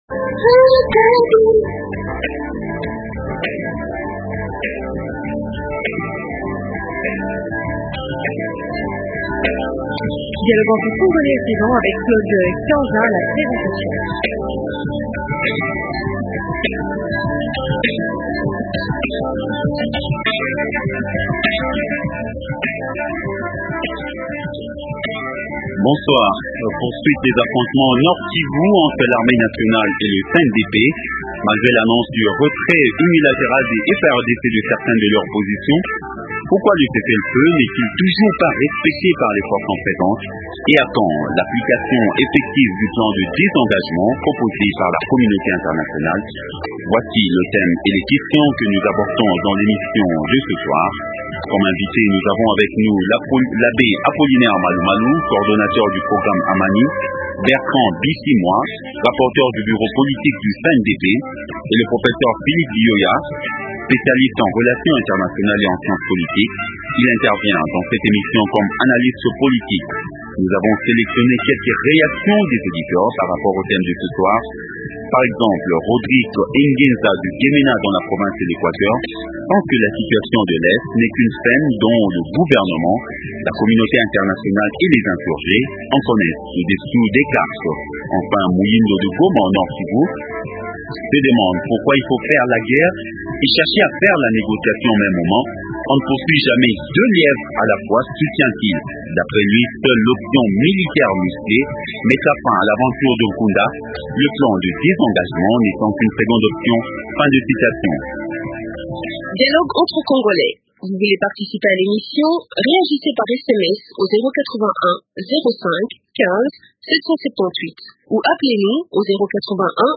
-Pourquoi le cessez-le feu n’est-il pas toujours respecté par les forces en présence ? -A quand l’application effective du plan de désengagement proposé par la communauté internationale ? Invités: -L’Abbé Apollinaire Malu Malu, coordonnateur du programme Amani.